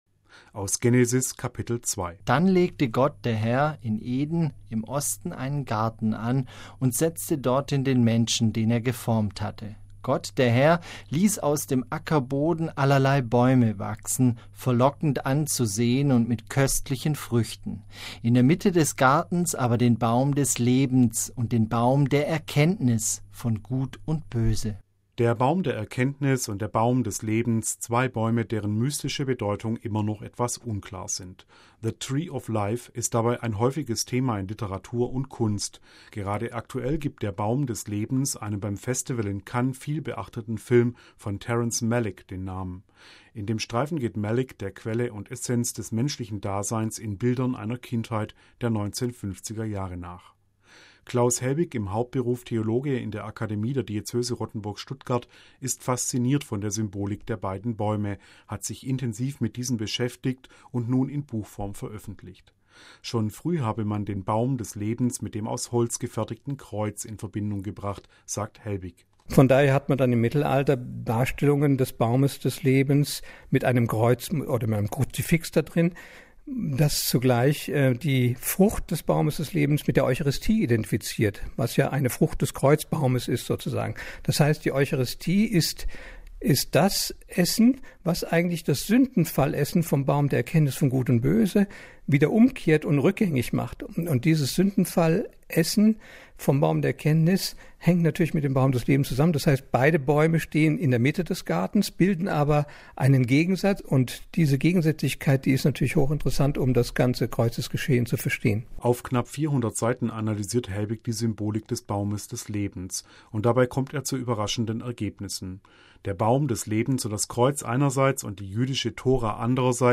MP3 Ein Stuttgarter Theologe ist aktuell ein gefragter Referent im Rahmen des christlich-jüdischen Dialogs.